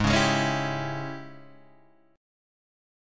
G# Major 7th Suspended 4th Sharp 5th